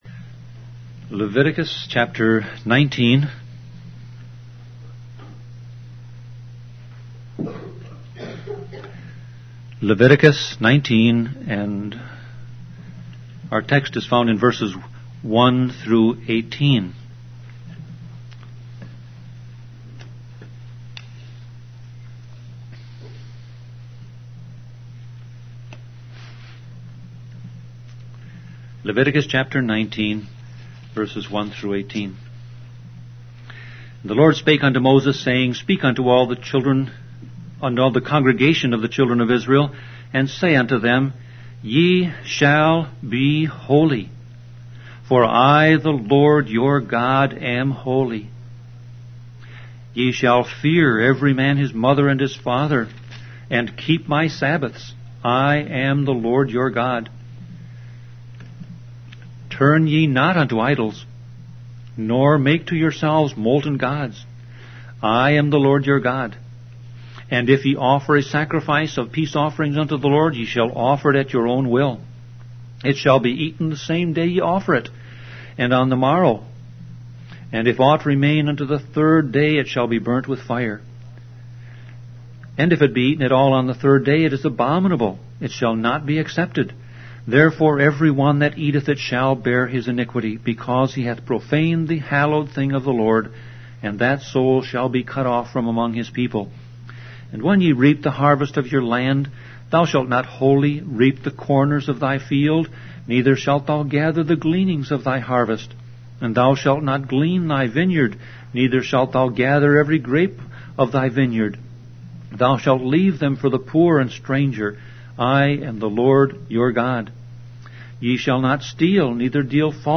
Sermon Audio Passage: Leviticus 19:1-18 Service Type